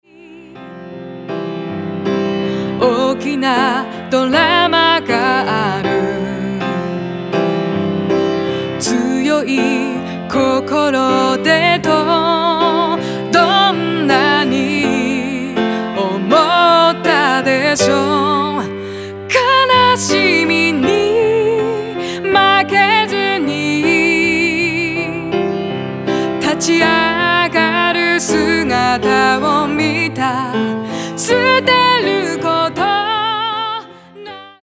Vo/Gt
Bass